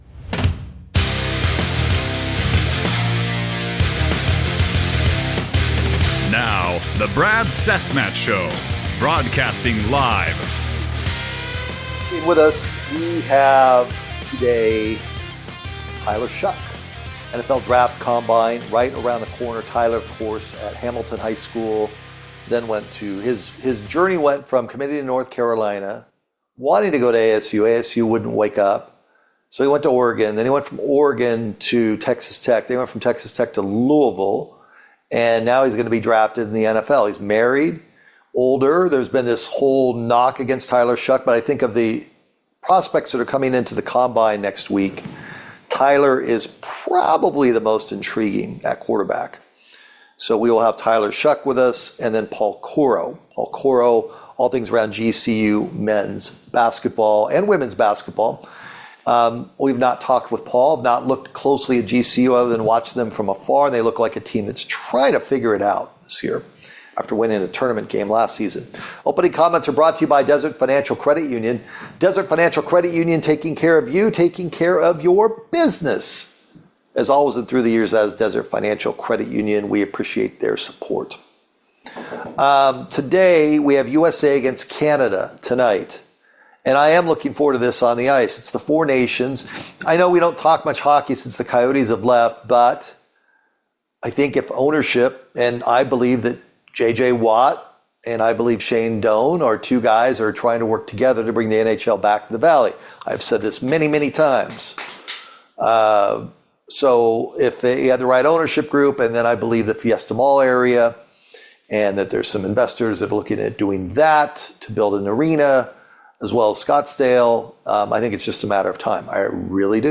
Tyler Shough Interview